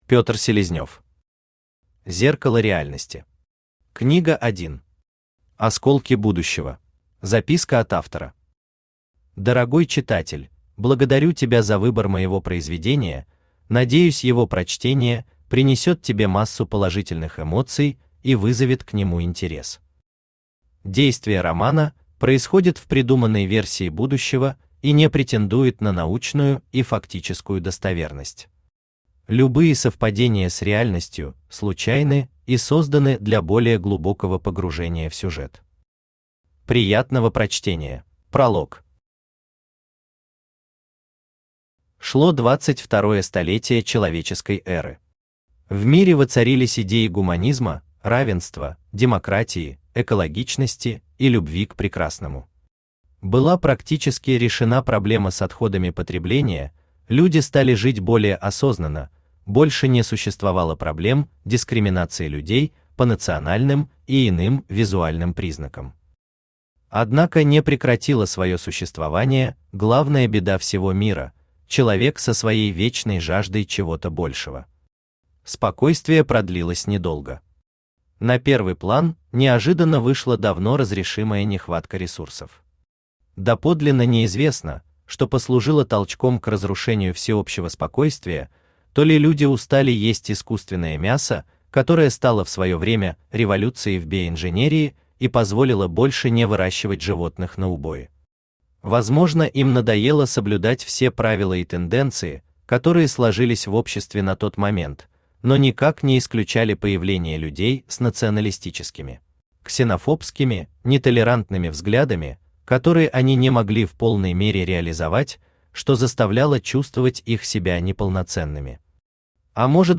Аудиокнига Зеркало реальности. Книга 1. Осколки будущего | Библиотека аудиокниг
Осколки будущего Автор Петр Селезнев Читает аудиокнигу Авточтец ЛитРес.